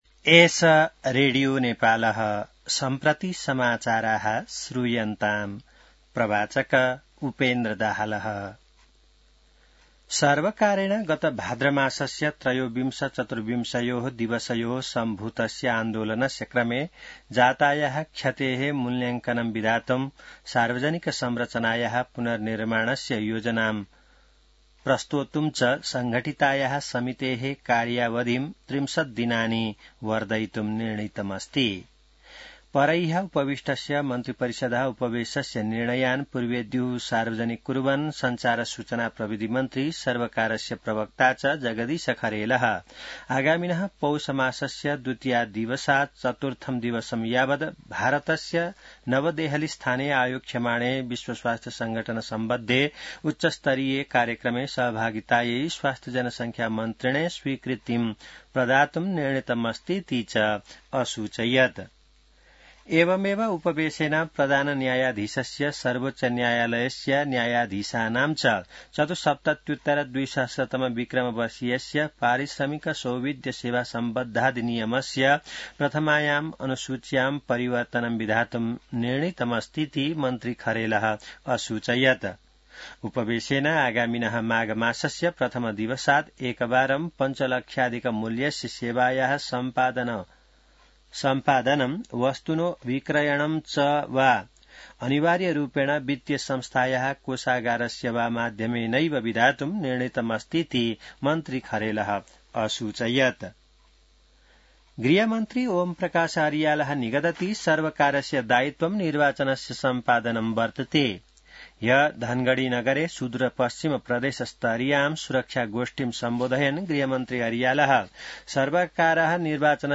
संस्कृत समाचार : १७ मंसिर , २०८२